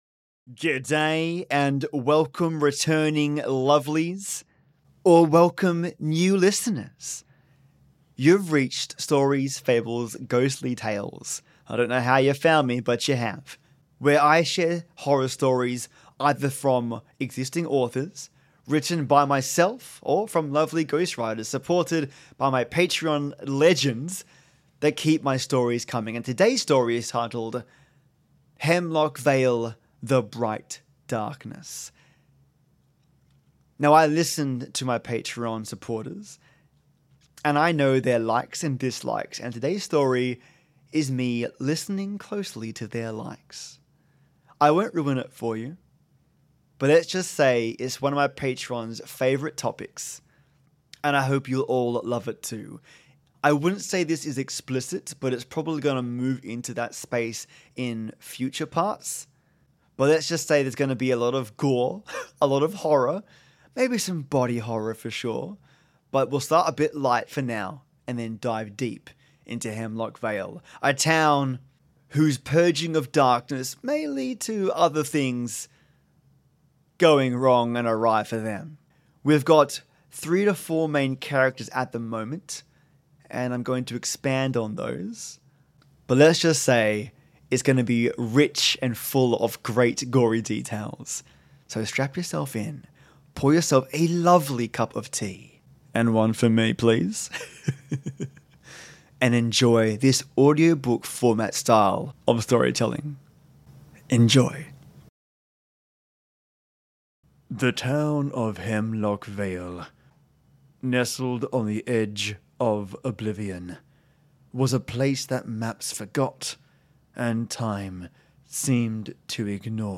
It was an absolute blast, using Artificial Intelligence to create the story, the audio, and the graphics.